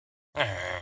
Villager_Angry.mp3